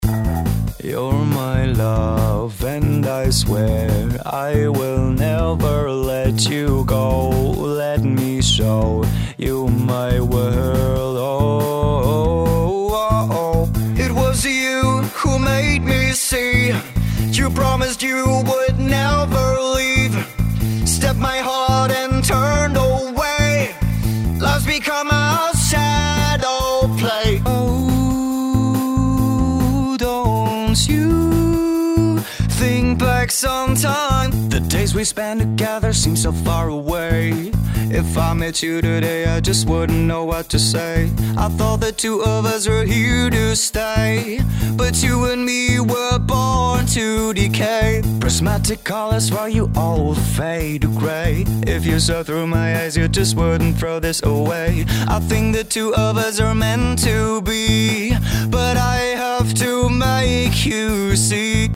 There are lots of different functions to play with from drums, bass, keyboard and vocals, and these are just the samples that come with the program.
After a few hours of playing around with the different instruments, sounds, vocals and learning how to easily fade in and out, mix and rearrange samples, I created my own basic sample (you can hear this